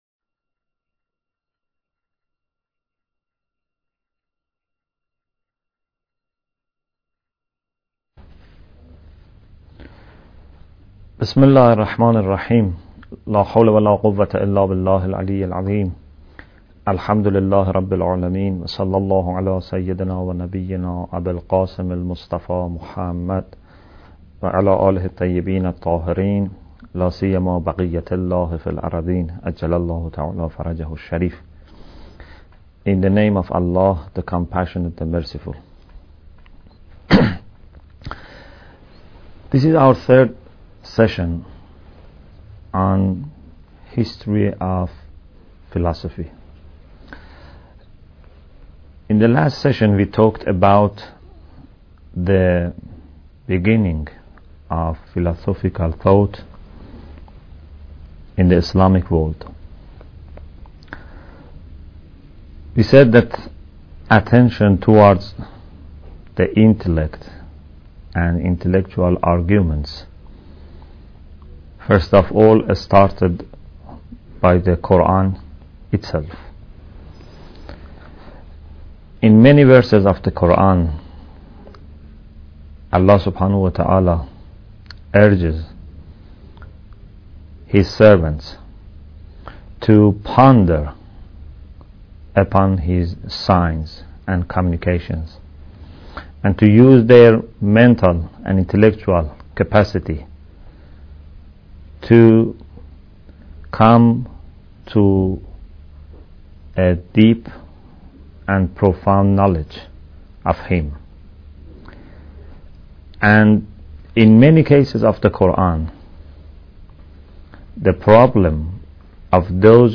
Bidayat Al Hikmah Lecture 3